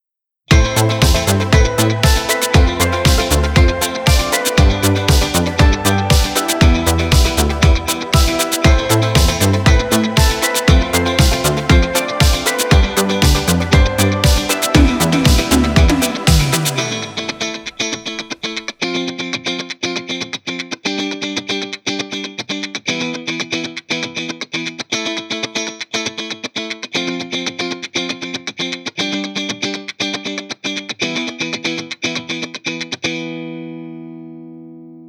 Italo Disco/ Euro Disco